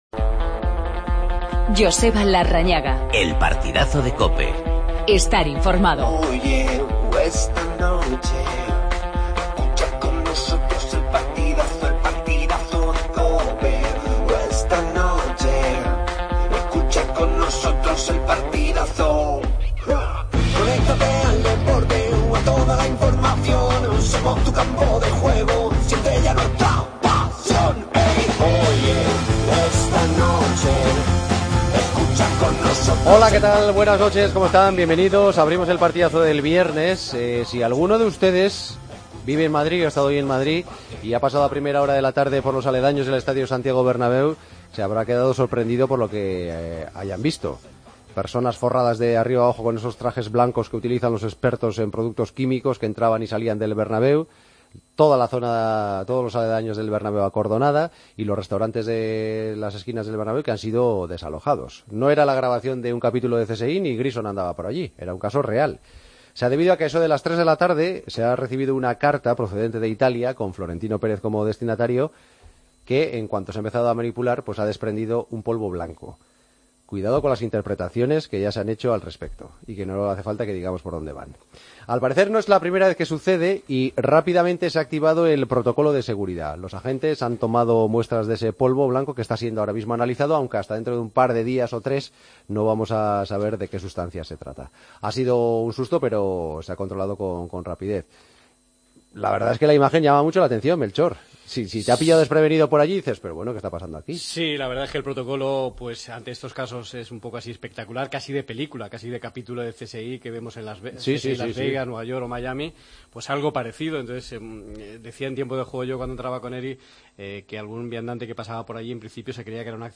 Susto en el Bernabéu con un sobre sospechoso dirigido a Florentino Pérez. Entrevista a Stoichkov.